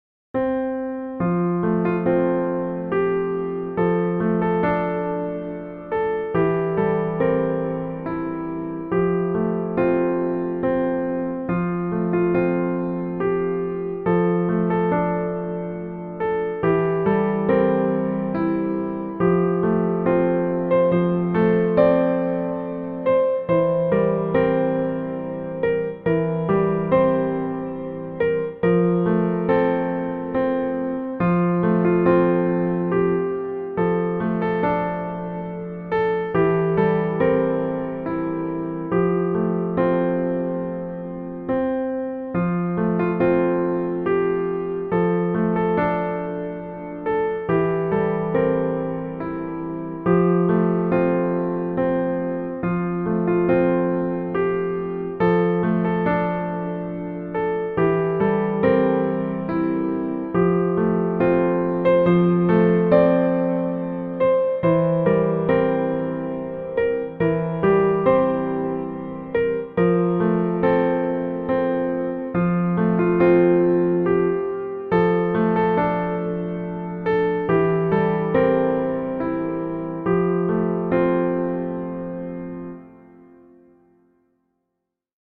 Genres: Relaxing Music
Tempo: slow